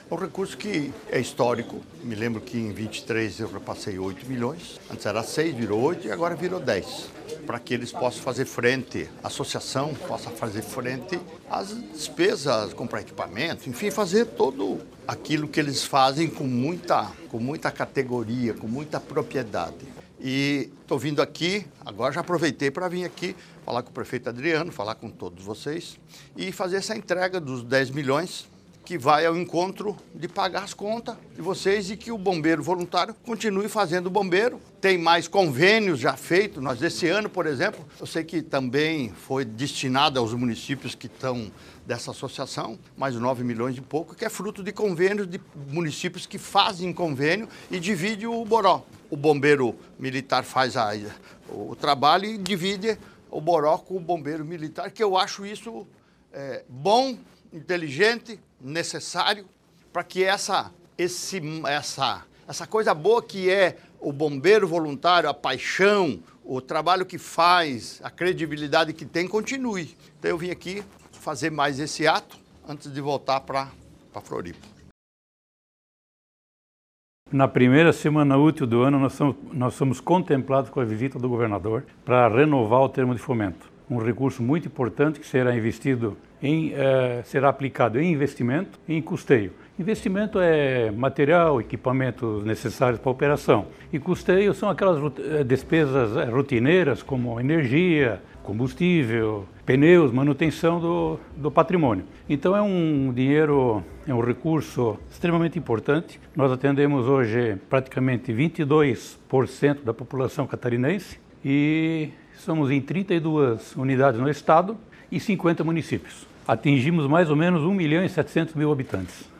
O ato ocorreu na sede da instituição em Joinville.
O governador Jorginho Mello ressalta a importância do trabalho realizado pelos bombeiros voluntários no estado: